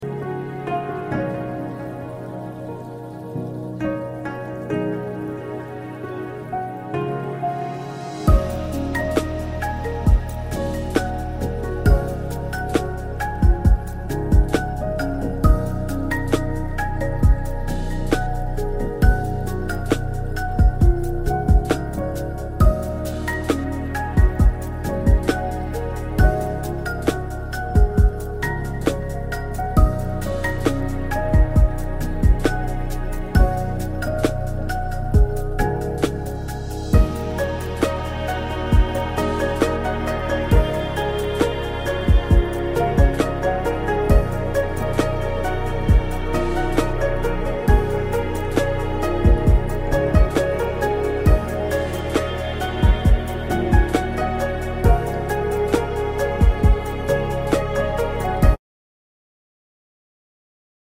Japanese Lofi HipHop Mix ~ Deep focus Study//Work